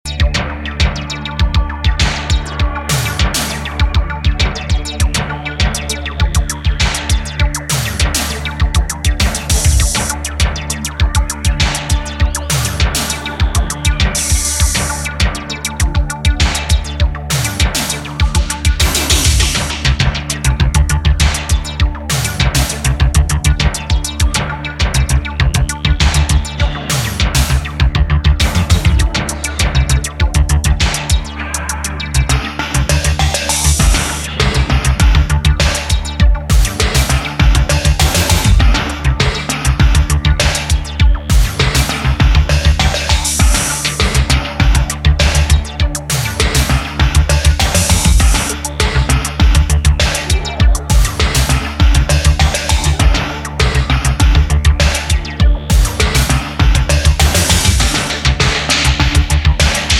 Genre Leftfield